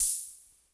Closed Hats
hat_08.wav